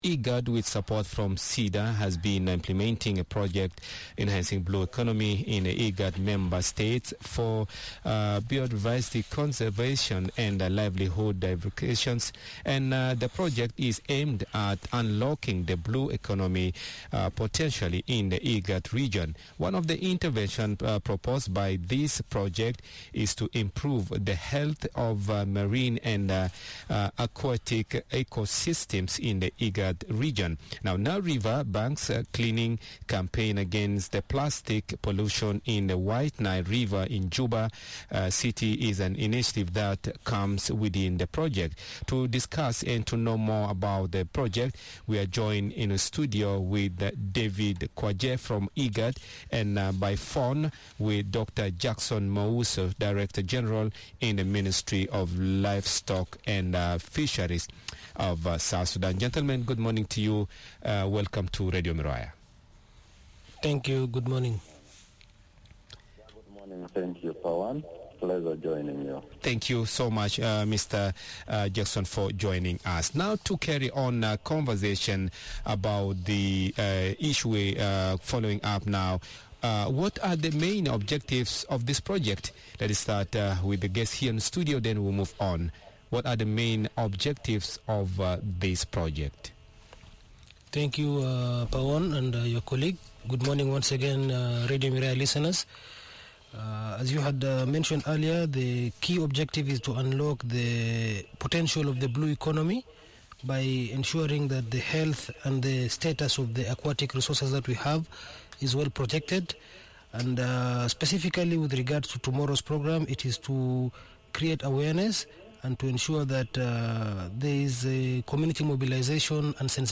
In South Sudan, IGAD supported by the Swedish International development and Cooperation Agency (SIDA) is partnering with the Juba City Council on a cleaning campaign against plastic pollution in the White Nile. IGAD Head of Mission in South Sudan, David Kwaje and Director General in the Ministry of Livestock and Fisheries Dr Jackson Muso explain the objectives of the campaign and give perspectives on the blue economy and how South Sudan stands to benefit from its aquatic resoources.